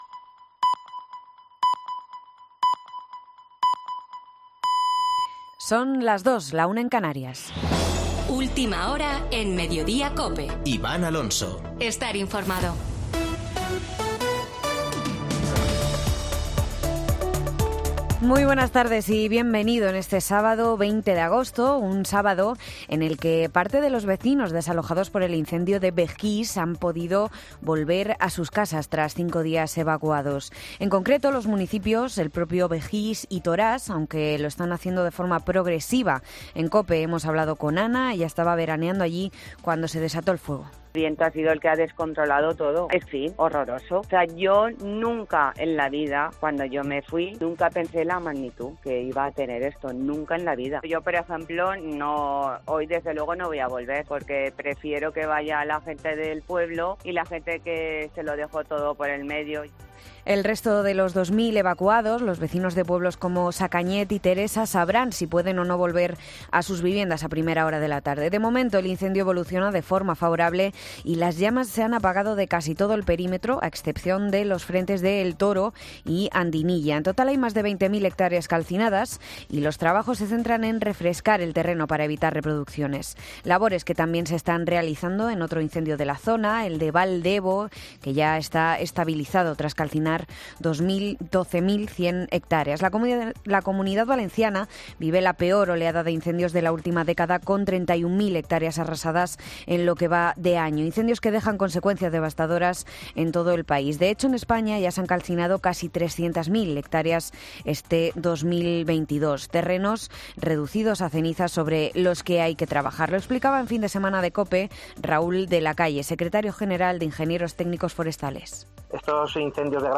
Boletín de noticias de COPE del 20 de agosto de 2022 a las 14.00 horas